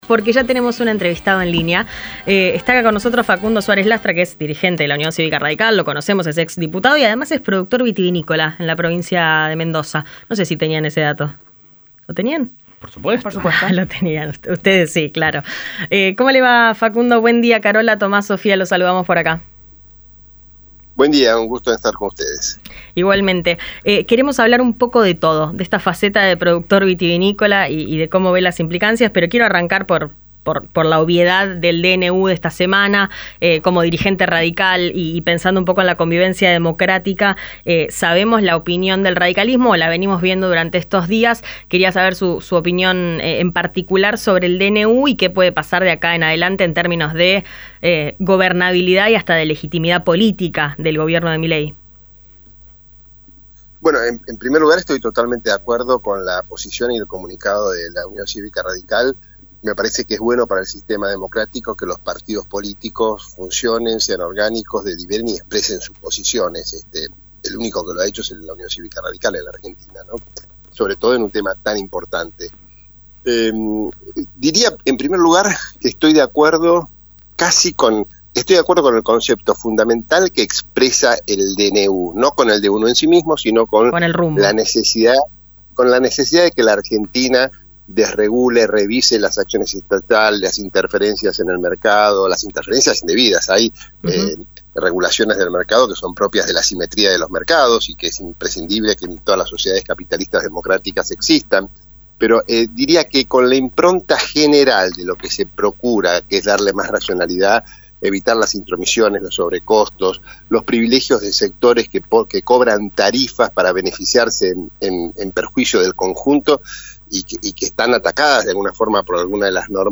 Facundo Suarez Lastra, Dirigente de la UCR y productor vitivinícola habló en Rivadavia Agro sobre las medidas que pretende el gobierno implementar sobre el sector.